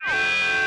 AFX_SPACEALARM_1_DFMG.WAV
Space Alarm 1